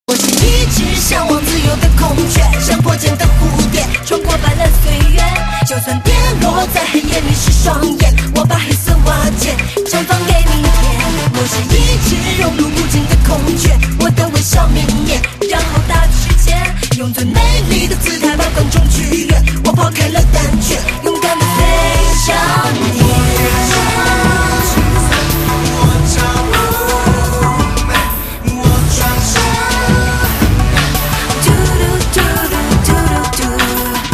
M4R铃声, MP3铃声, 华语歌曲 60 首发日期：2018-05-15 14:13 星期二